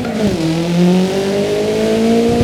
Index of /server/sound/vehicles/lwcars/renault_alpine